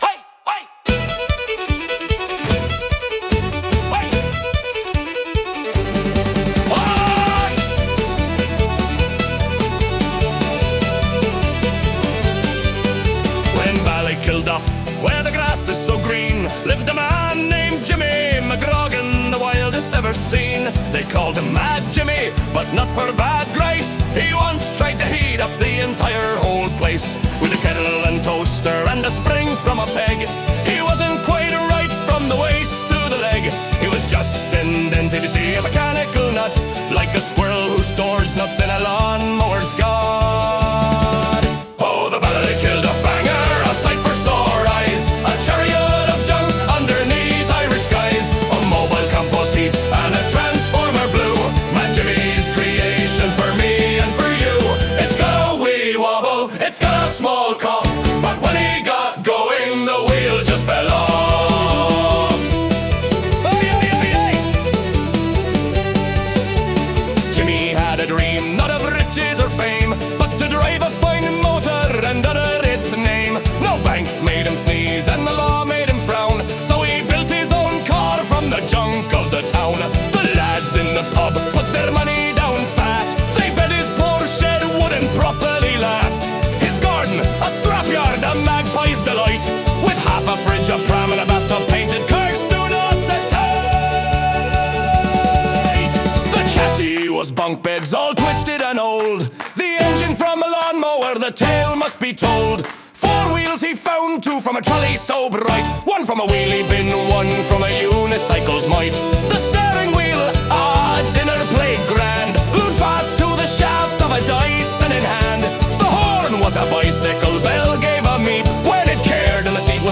Posted by on December 2, 2025 in ballykillduff, beer, fantasy, fantasy story, funny song, funny story, humor, humour, pub song, singalong